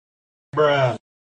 bruh-sound-effect-2-mp3-indir-2zipfytcsvc.mp3